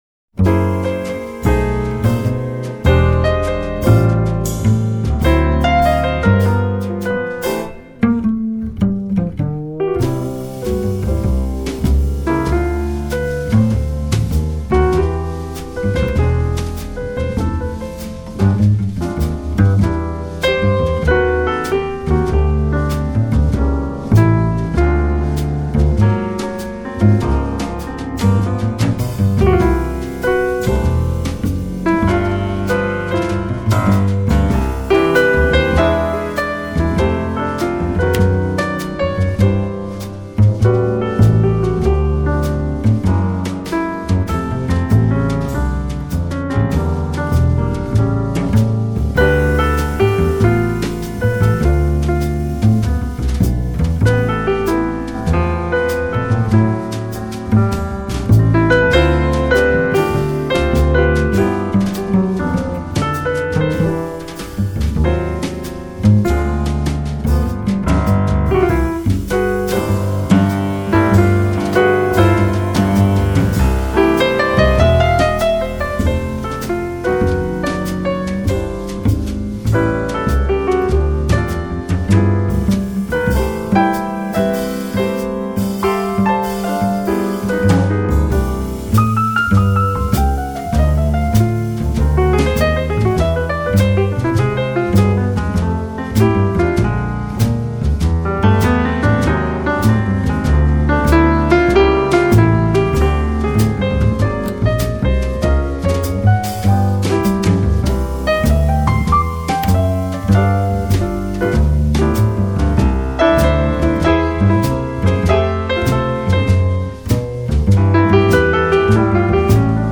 的睿智与优雅，但是他的钢琴很流畅，很细腻， 是温柔而沉静的，你不需要
爵士鋼琴專輯